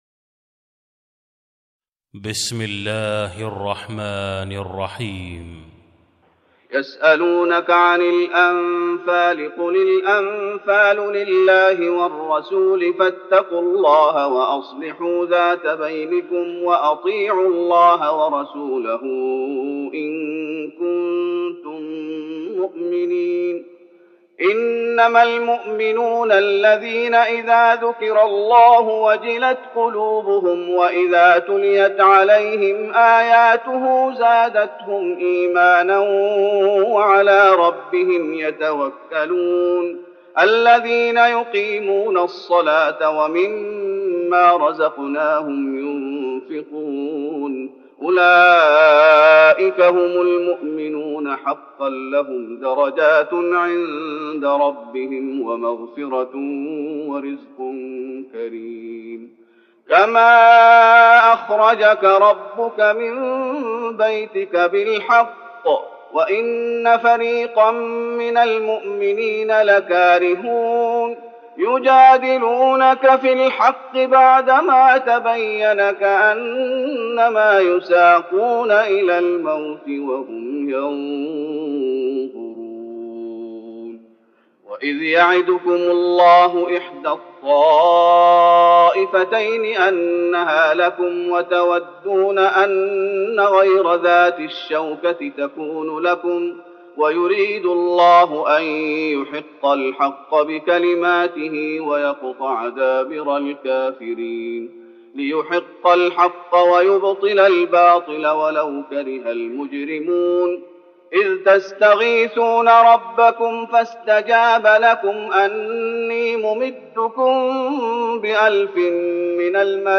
تراويح رمضان 1413هـ من سورة الأنفال (1-40) Taraweeh Ramadan 1413H from Surah Al-Anfal > تراويح الشيخ محمد أيوب بالنبوي 1413 🕌 > التراويح - تلاوات الحرمين